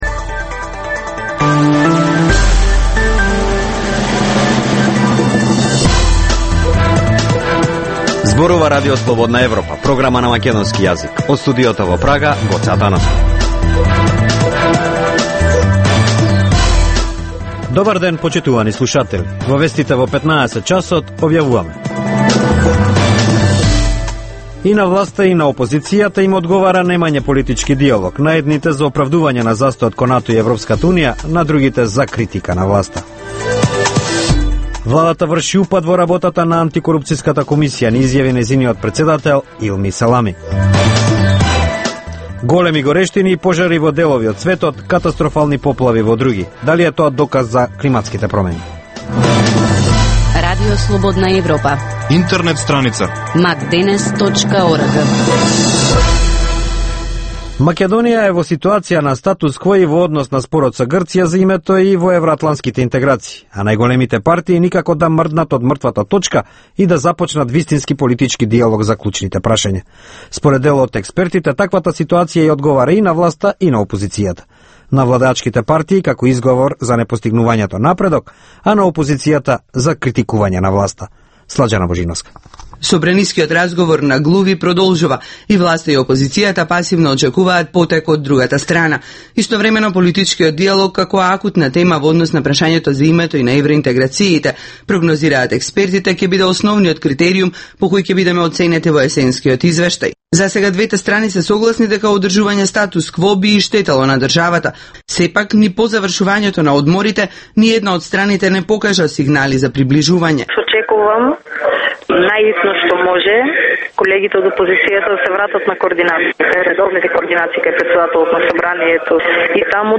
Информативна емисија, секој ден од студиото во Прага. Вести, актуелности и анализи за случувања во Македонија на Балканот и во светот.